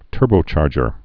(tûrbō-chärjər)